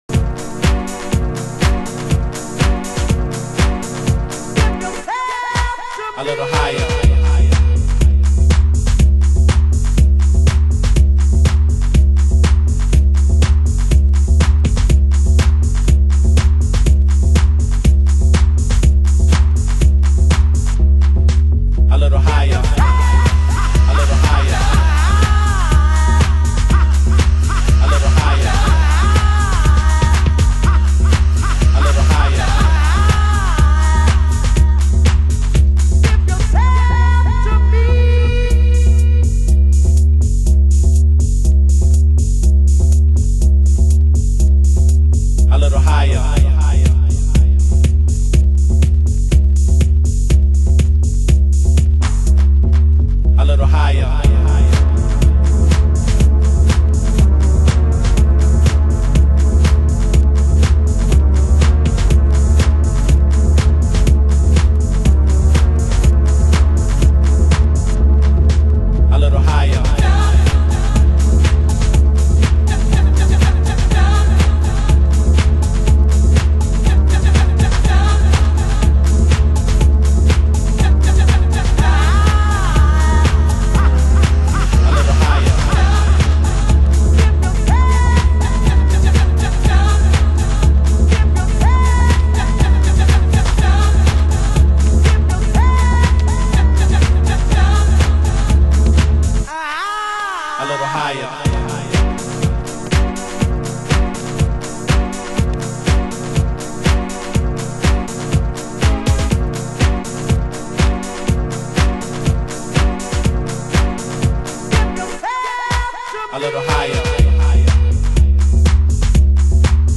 HOUSE MUSIC USED ANALOG ONLINE SHOP